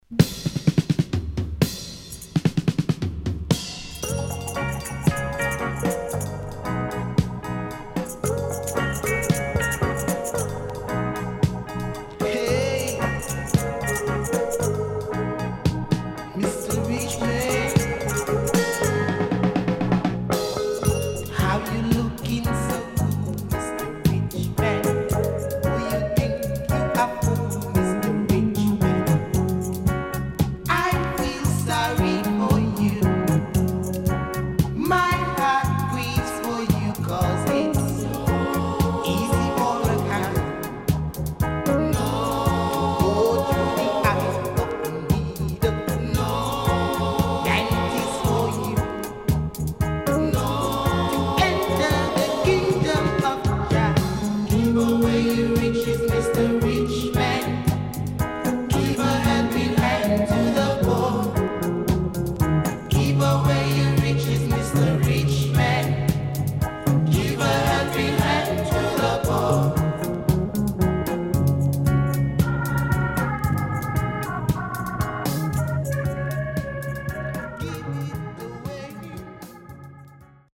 HOME > Back Order [VINTAGE DISCO45]  >  KILLER & DEEP
Killer Vocal & Nice Tune.W-Side Good.両面後半Dub接続
SIDE A:少しチリノイズ、プチノイズ入ります。